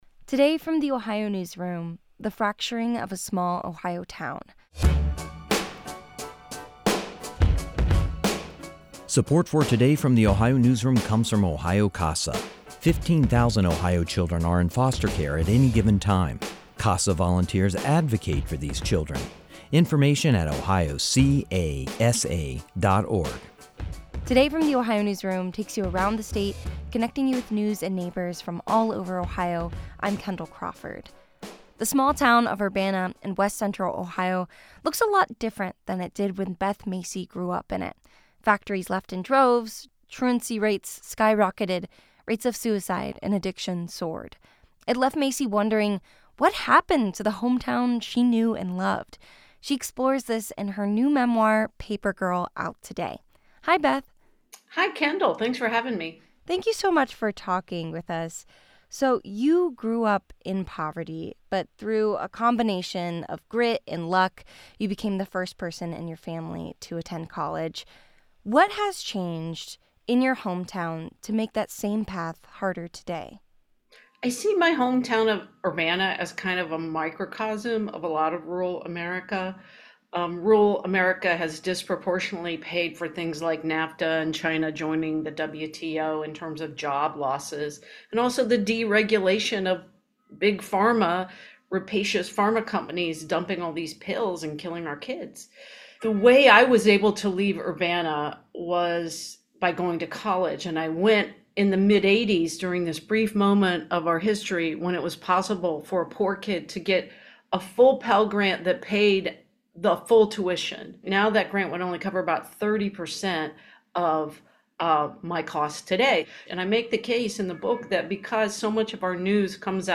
Macy sat down with The Ohio Newsroom to talk about her book.
This interview has been edited lightly for brevity and clarity.